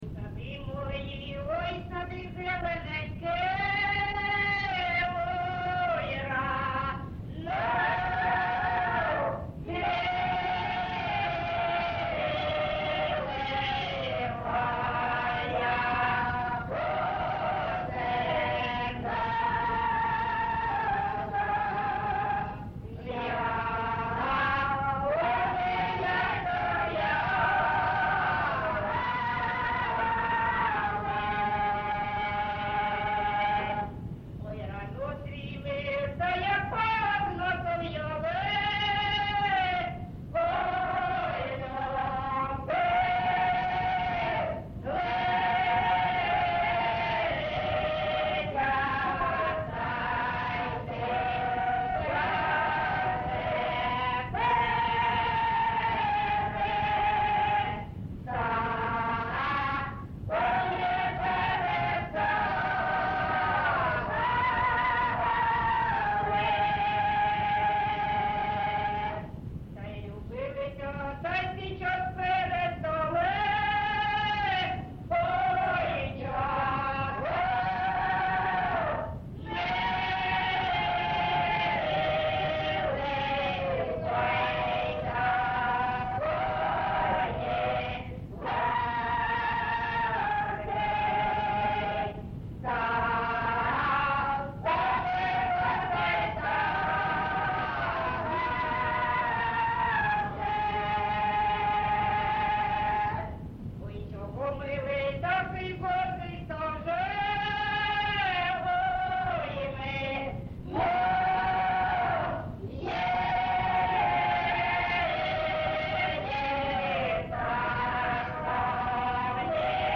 ЖанрПісні з особистого та родинного життя
Місце записус. Семенівка, Краматорський район, Донецька обл., Україна, Слобожанщина